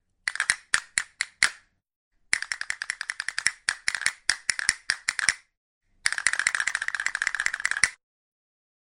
Звук кастаньет
Музыкальные эффекты